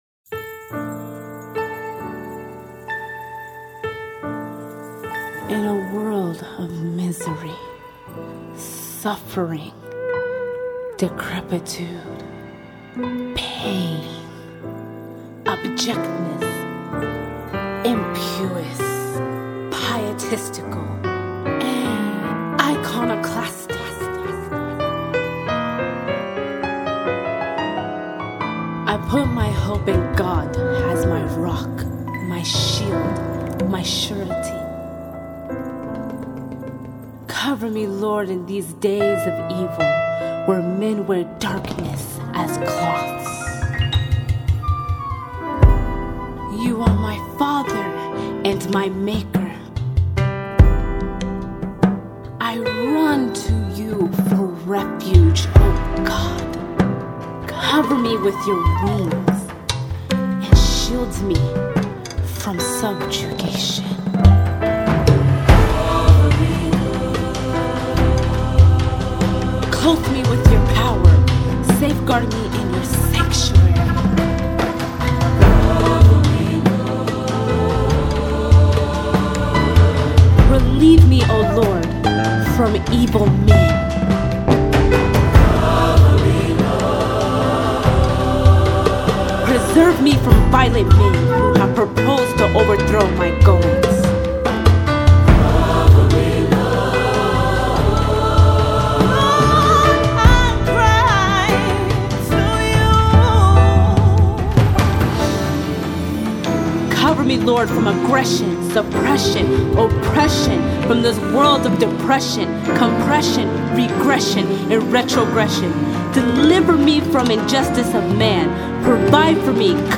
Gospel
features a 150 voice choir
piano